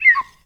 whistle_slide_down_04.wav